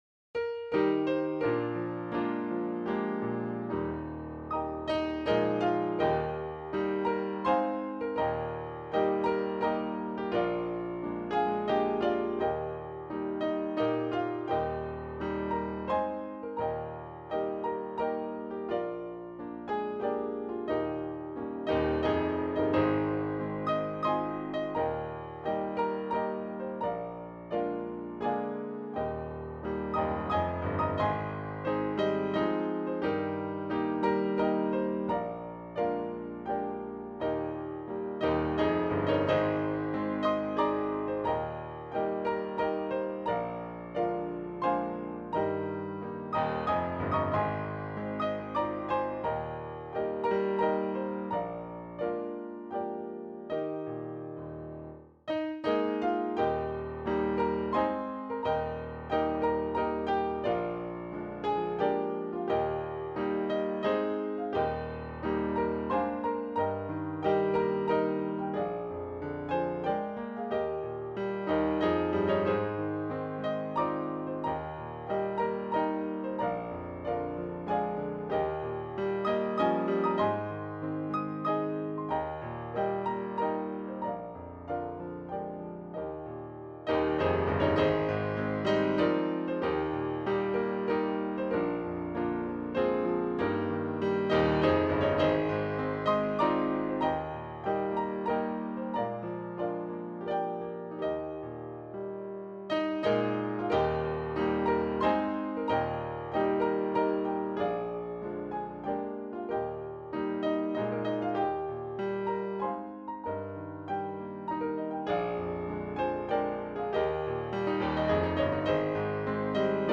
Key: E♭